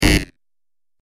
دانلود صدای خطا 6 از ساعد نیوز با لینک مستقیم و کیفیت بالا
جلوه های صوتی